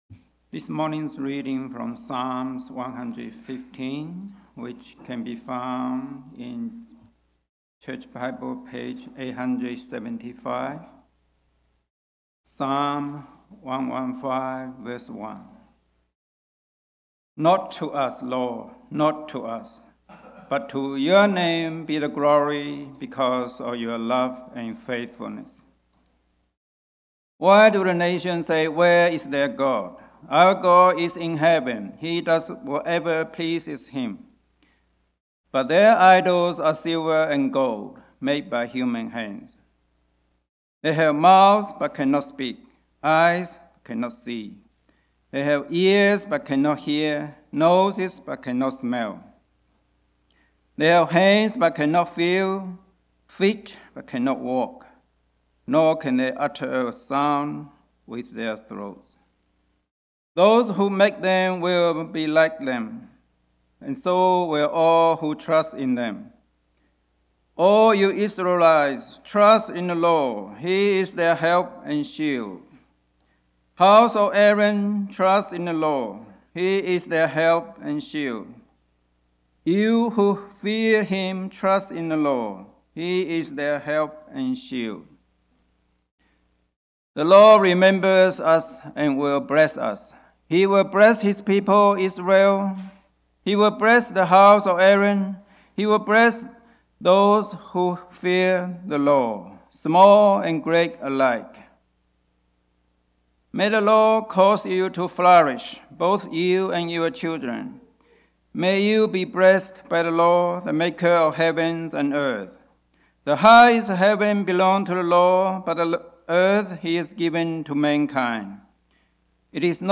Sermons – Reservoir Presbyterian Church